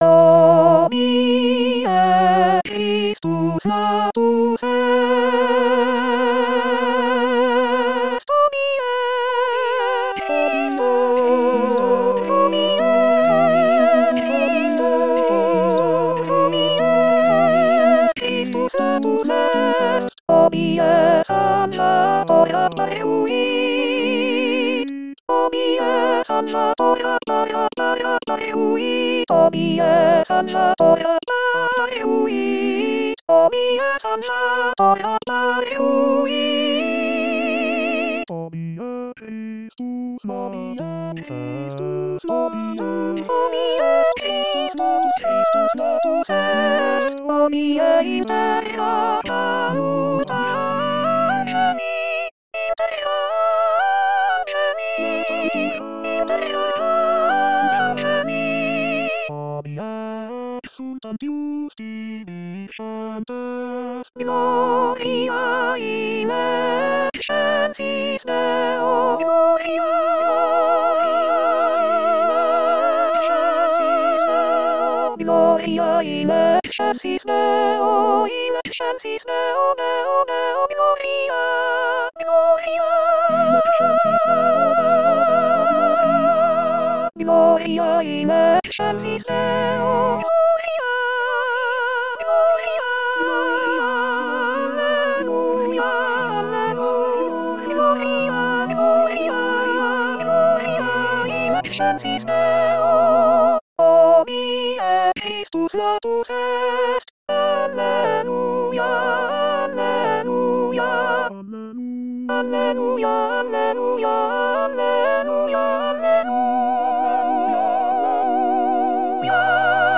Soprano Soprano 1